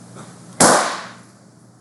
Balloon Pop
balloon loud pop sound effect free sound royalty free Sound Effects